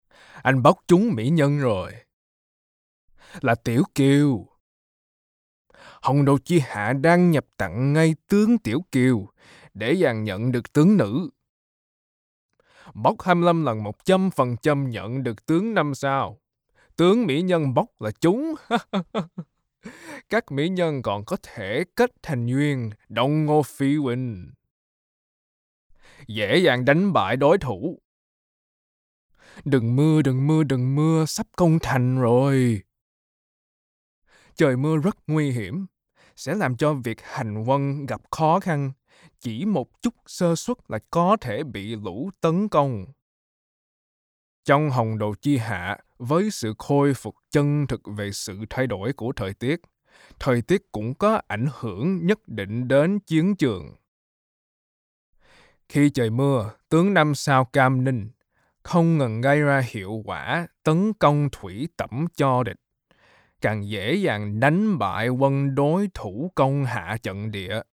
专业游戏配音